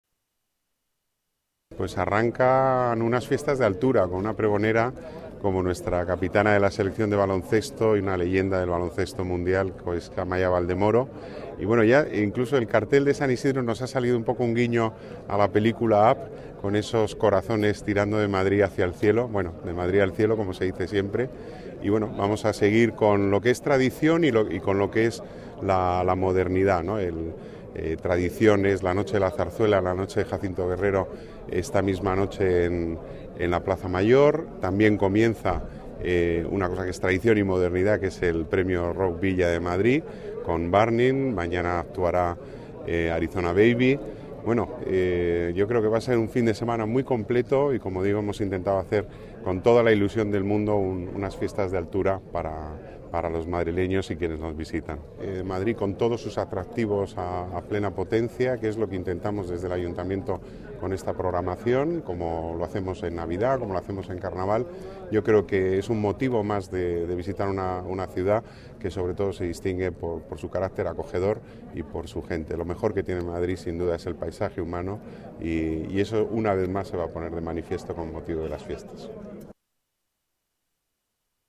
Nueva ventana:Declaraciones del concejal delegado de Las Artes, Pedro Corral: Arranca San Isidro